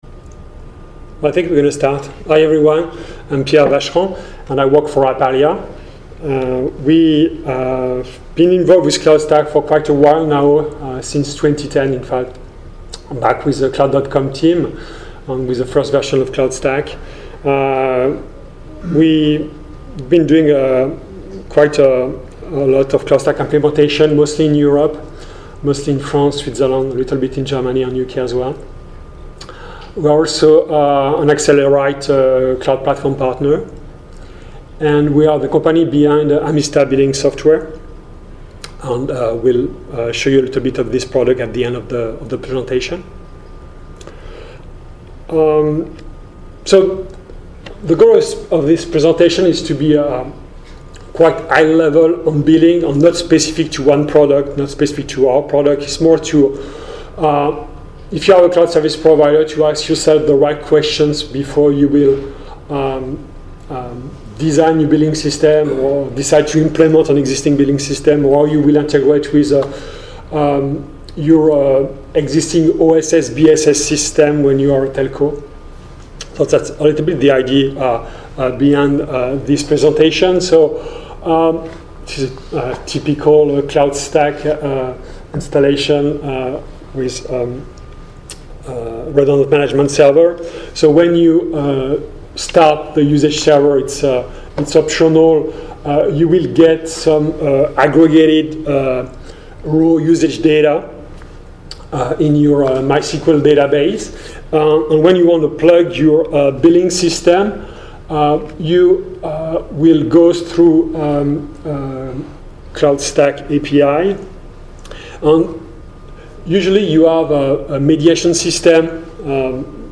APALIA Cloudstack Collaboration Conference